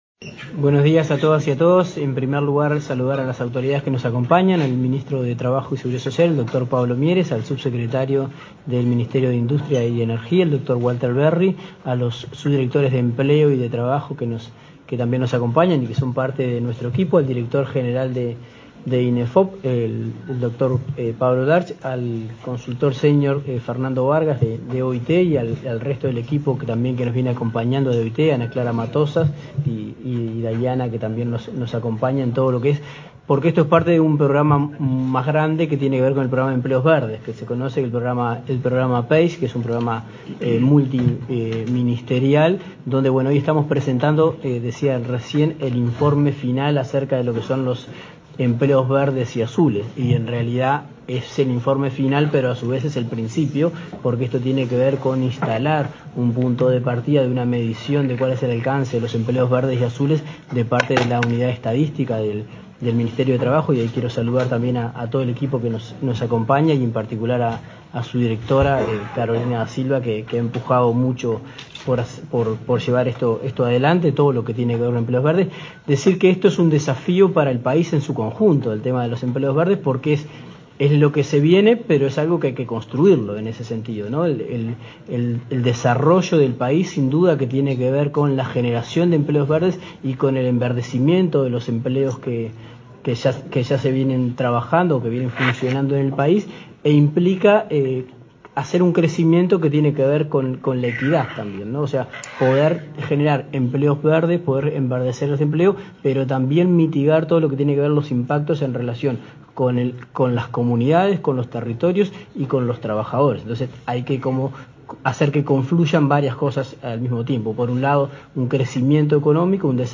Palabras de autoridades del Ministerio de Trabajo y Seguridad Social
Este miércoles 16, el ministro de Trabajo, Pablo Mieres, y el director nacional de Empleo, Daniel Pérez, participaron en la presentación del informe